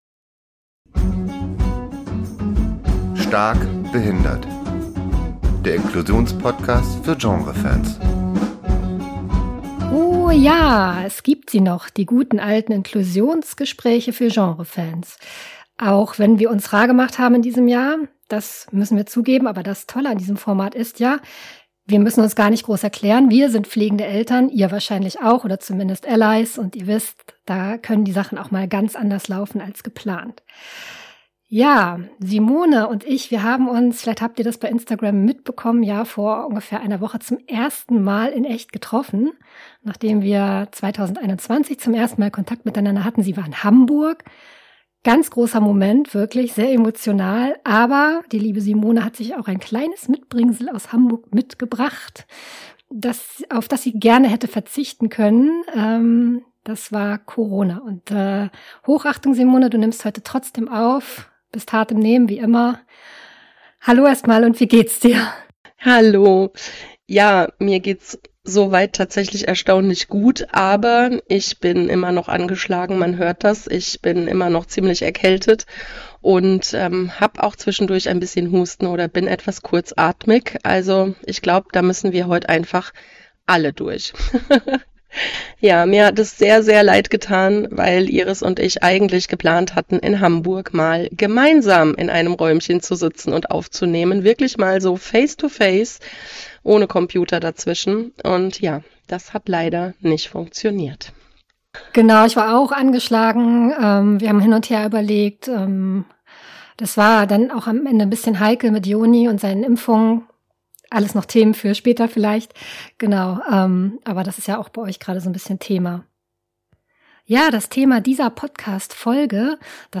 Sie kommen oft wie aus dem Nichts und rühren uns dann zu Tränen: Augenblicke, in denen wir begreifen, wie großartig das Leben mit einem behinderten Kind ist. Ein Gespräch über Gänsehautmomente, mit denen wir nie gerechnet hätten - und die ganz große Dankbarkeit.